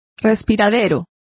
Complete with pronunciation of the translation of vent.